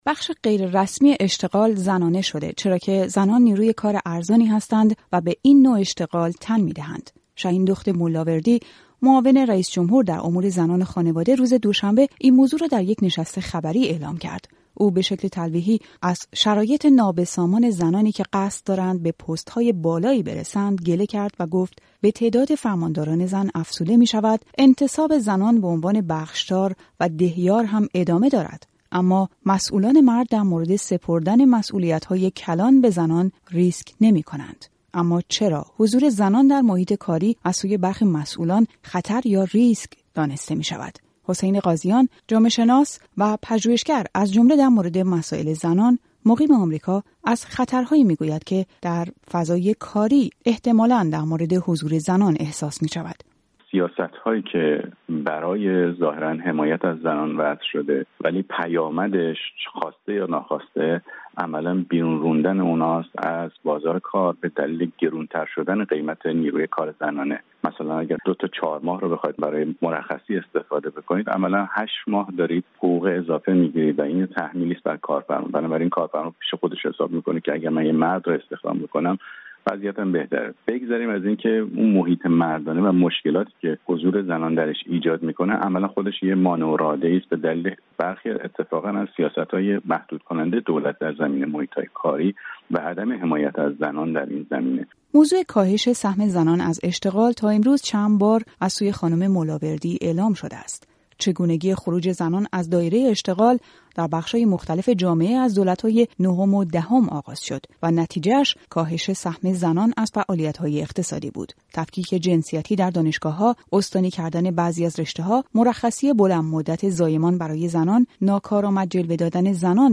گزارش رادیو فردا در مورد فعالیت زنان در بخش غیررسمی اشتغال